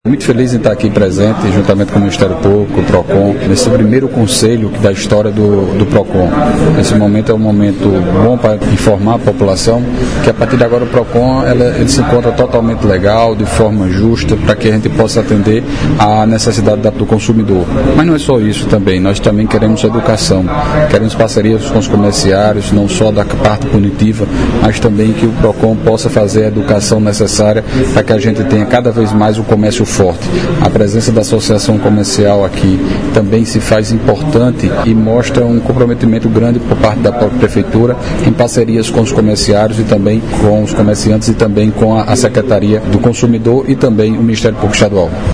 Foi realizada na tarde desta quarta-feira (05/04), na sede do SAMU, a solenidade de criação do Conselho Municipal de Defesa do Consumidor.
Fala do prefeito Dinaldinho Wanderley –